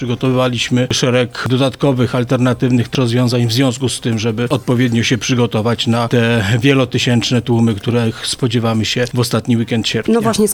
Jednak w Mocnej Rozmowie wiceminister obrony narodowej Wojciech Skurkiewicz uspokaja: